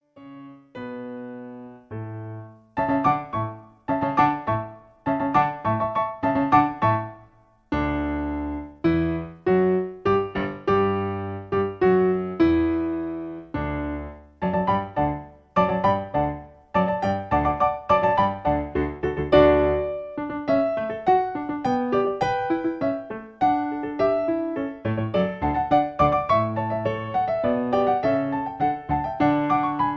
Original Key (D). Piano Accompaniment